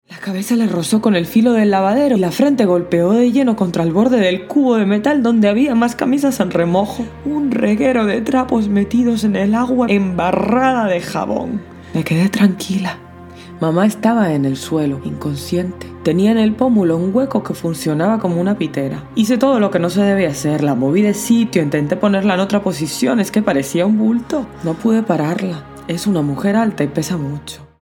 Versatile, naturally mature, essentially deep, inspiring, exciting, creative, surprising voice!
Sprechprobe: eLearning (Muttersprache):
Vocal age between 15 and 50 years.
CUENTO SPA - ACENTO CUBANO.mp3